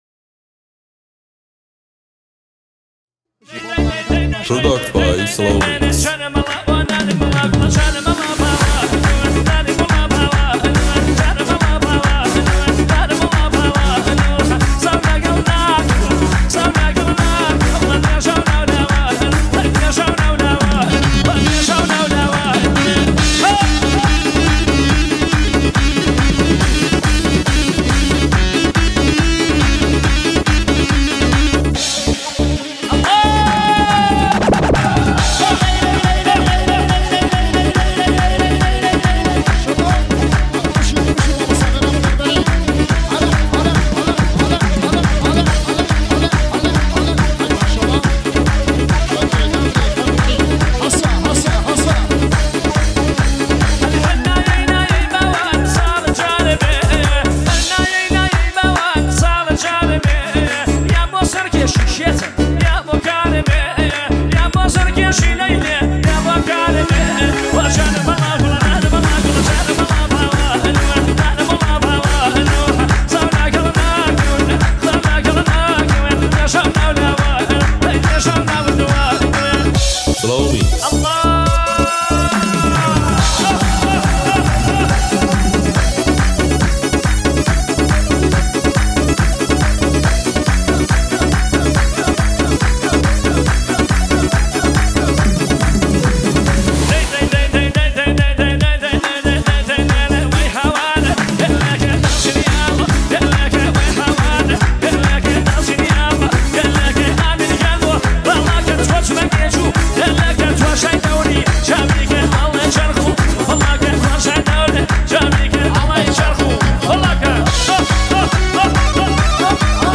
remix kurdi